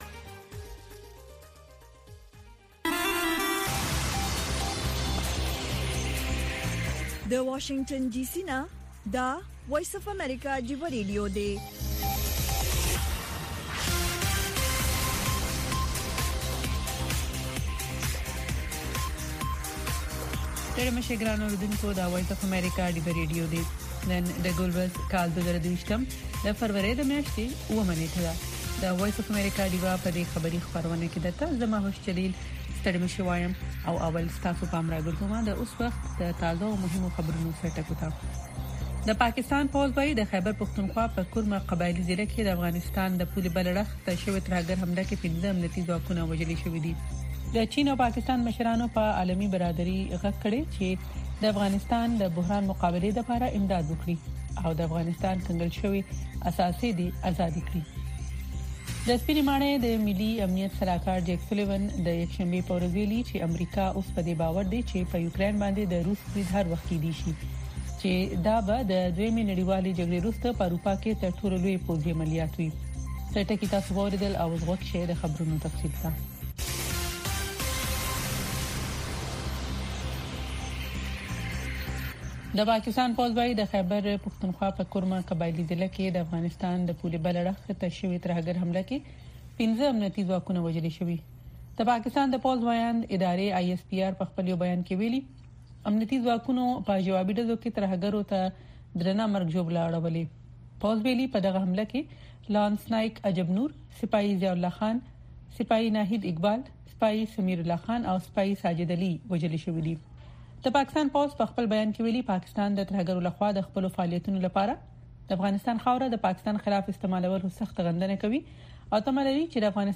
خبرونه
د وی او اې ډيوه راډيو سهرنې خبرونه چالان کړئ اؤ د ورځې د مهمو تازه خبرونو سرليکونه واورئ.